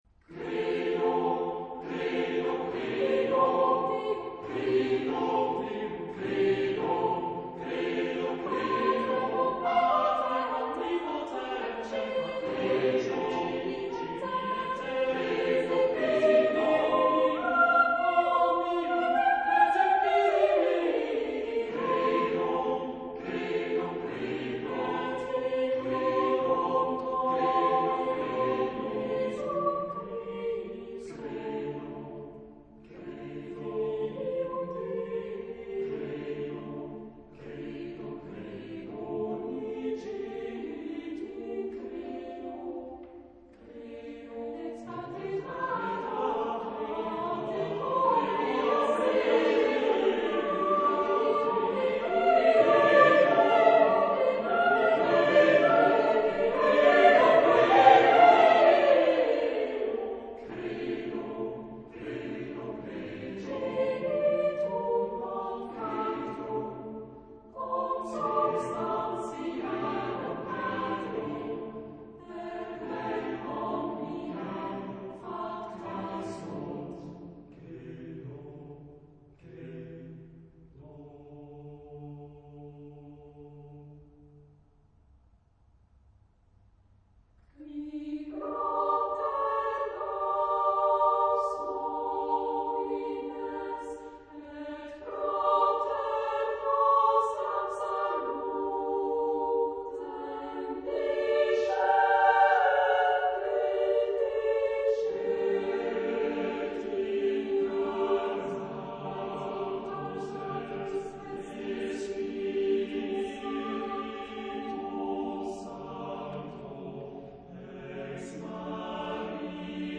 Genre-Style-Forme : Sacré ; Messe
Caractère de la pièce : religieux ; ferme
Type de choeur : SSATTB  (6 voix mixtes )
Tonalité : fa (centré autour de)